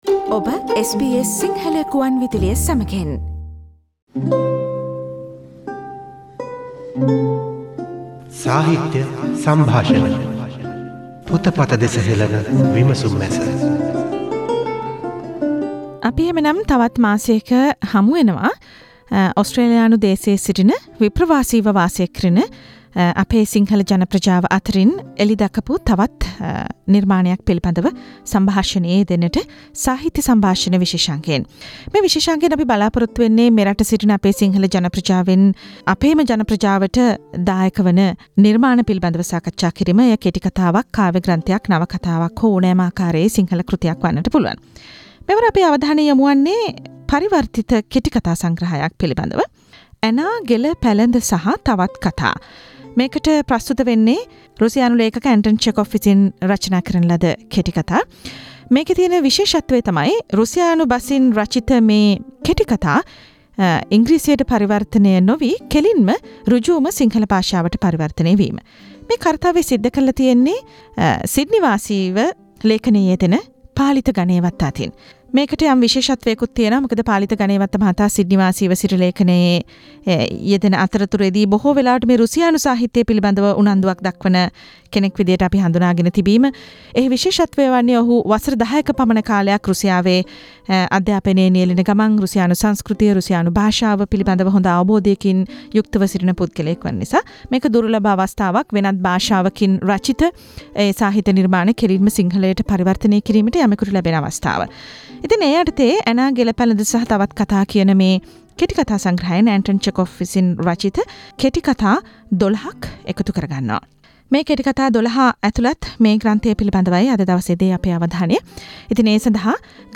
SBS Sinhala Radio monthly book review program focuses on a Sinhala translation of Anton Chekov’s Russian short story collection